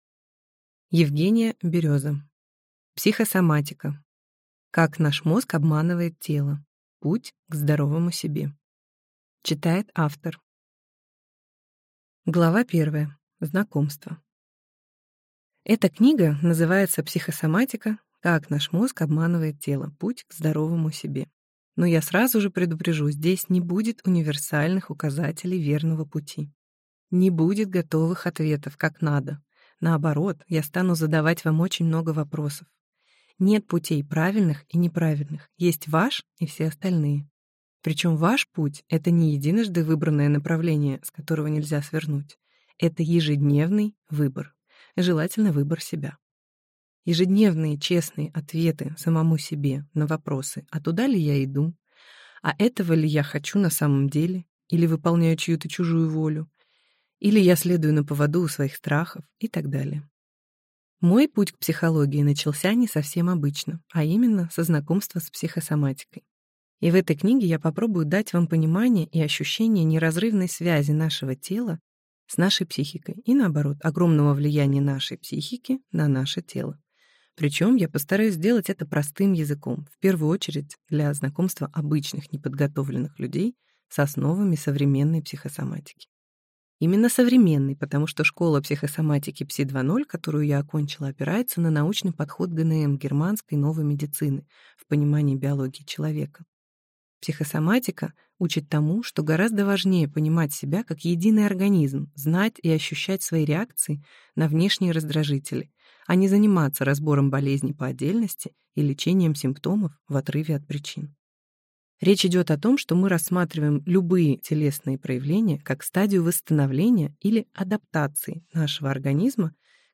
Аудиокнига Психосоматика: как наш мозг обманывает тело. Путь к здоровому себе | Библиотека аудиокниг